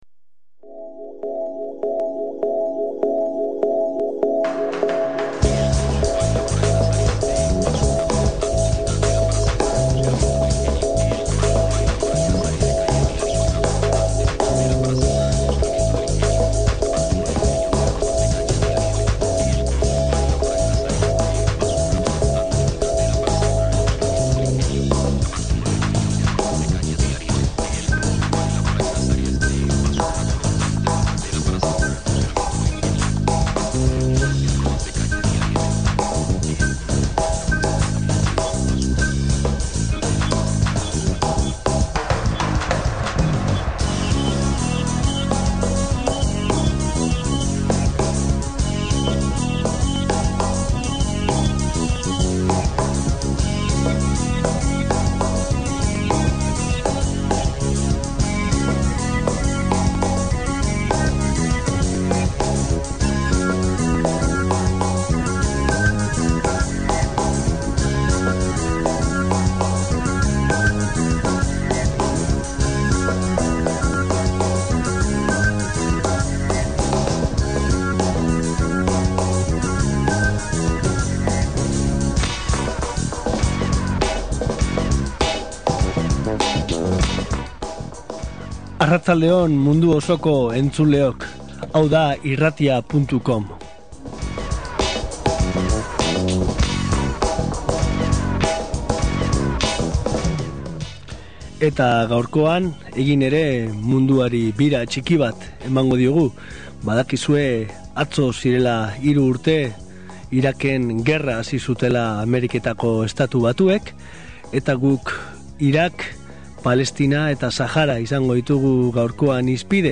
Nazioarteko egoerari begira jarri gara gaurkoan Bilbo Hiria irratian egin dugun saio berezian. 3 urte dira Ameriketako Estatu Batuek Iraken gerra hasi zutela